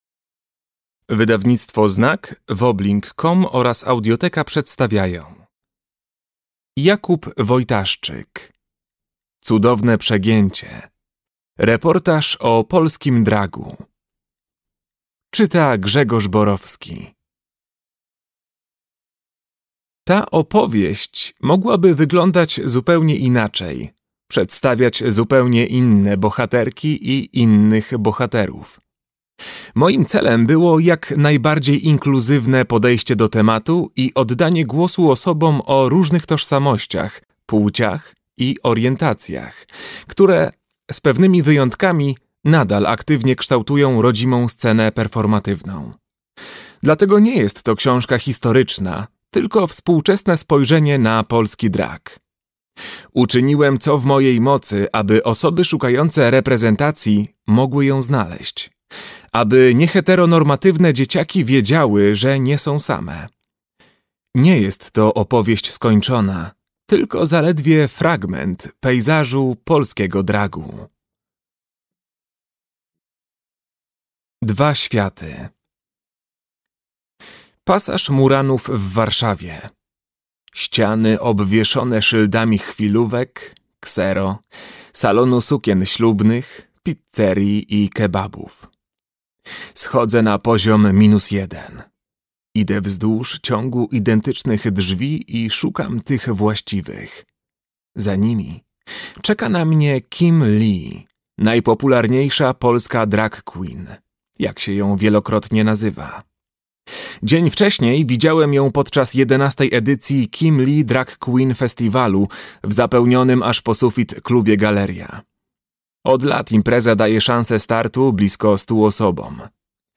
Cudowne przegięcie. Reportaż o polskim dragu - Jakub Wojtaszczyk - audiobook